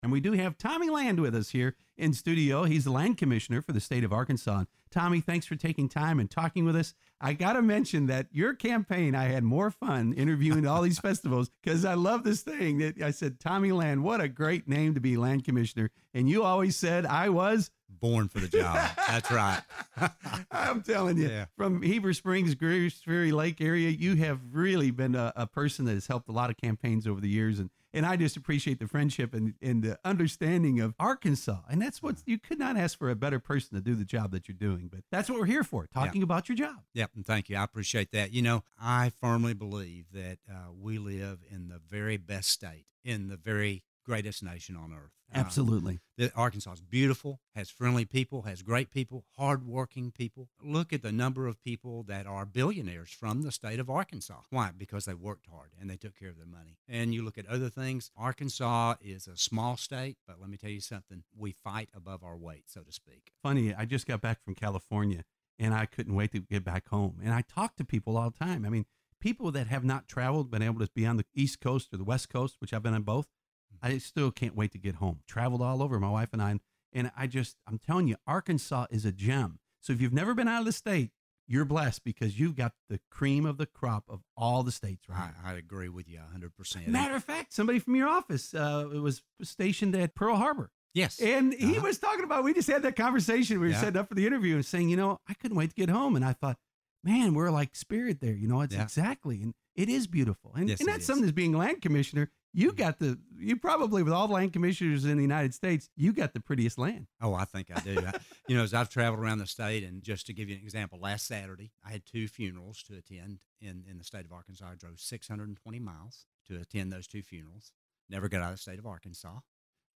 KFFB’s Open Mic was heard on October 18, 2019, at 7:50 am and 5:50 pm.